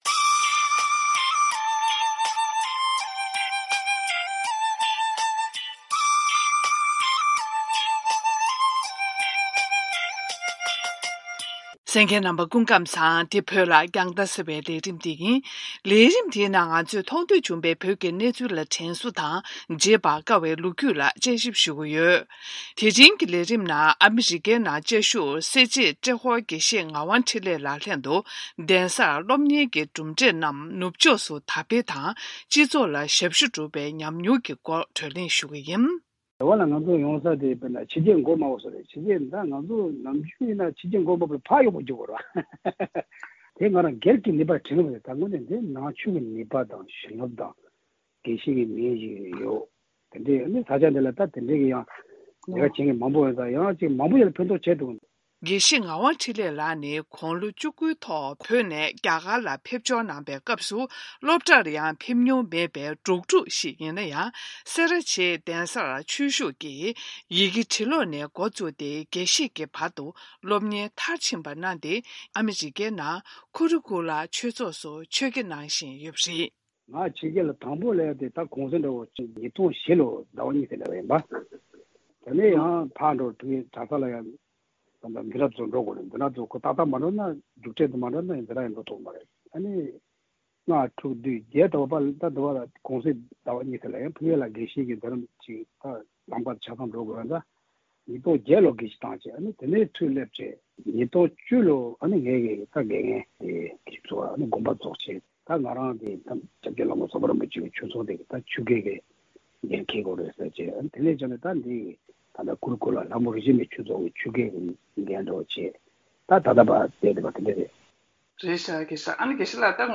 ཉམ་མྱོང་གླེང་མོལ་ཞུས་པ་ཞིག་གསན་རོགས་གནང་།